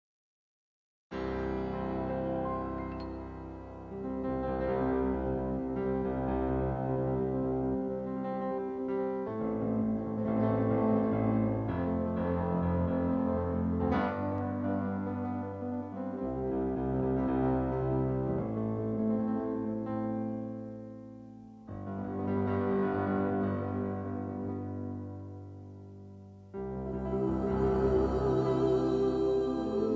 backing tracks
easy litstening, top 40, classic rock